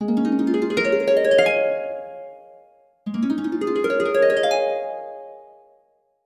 ubuntu-touch-harp-arp_24616.mp3